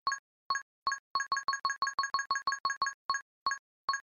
turntable_1.mp3